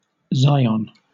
Ääntäminen
Southern England: IPA : /ˈzaɪ.ən/